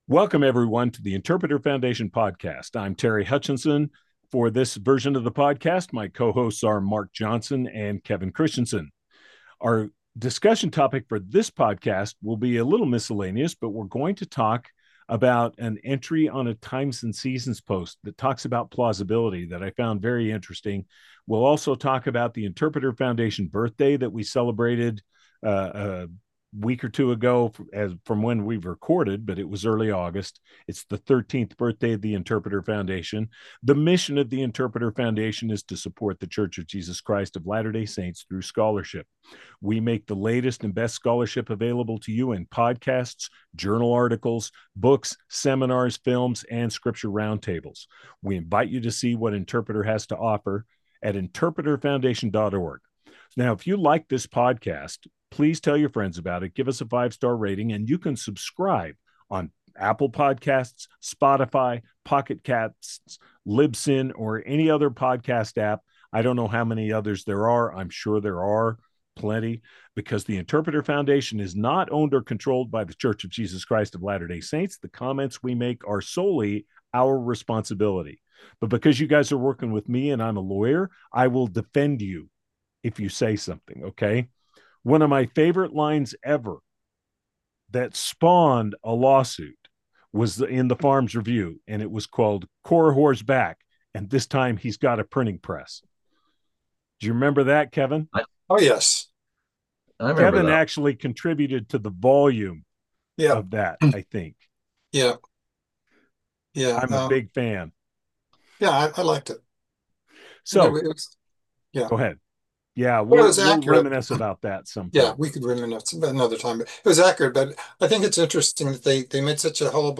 Podcast: Download The Interpreter Foundation Podcast is a weekly discussion of matters of interest to the hosts and guests of the show.